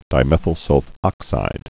(dī-mĕthəl-sŭl-fŏksīd)